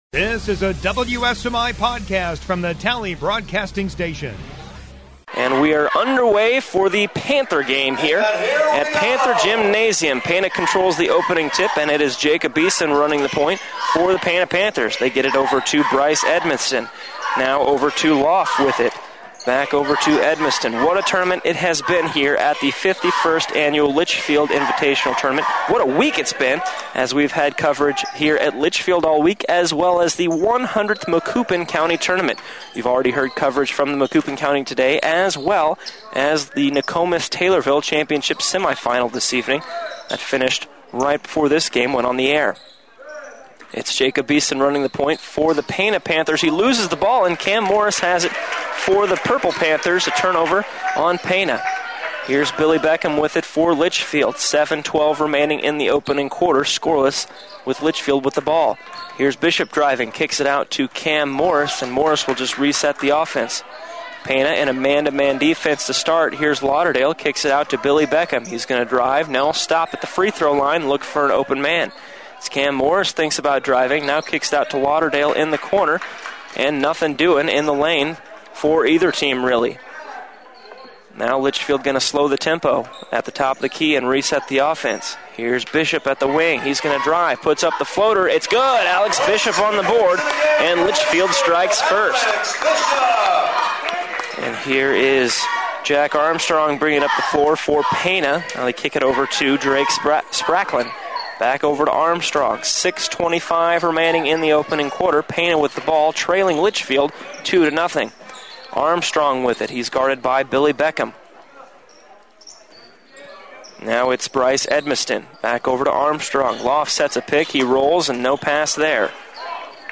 Podcasts - HS Sports Coverage